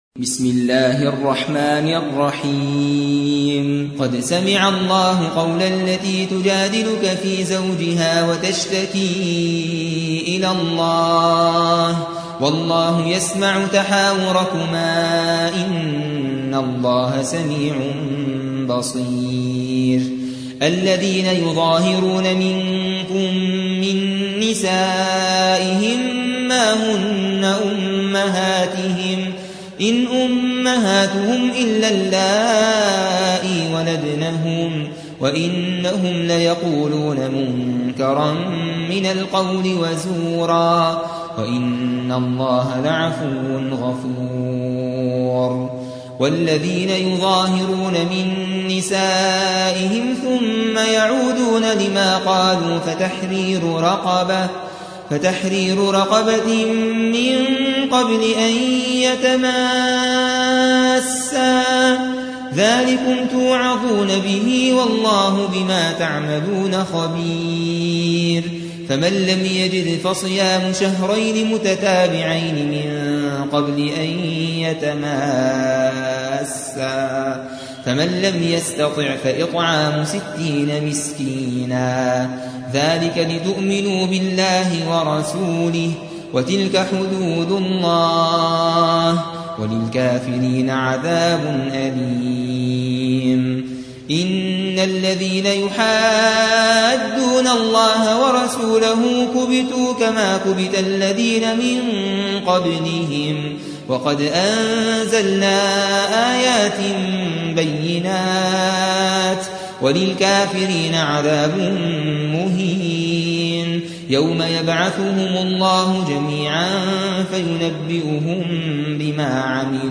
58. سورة المجادلة / القارئ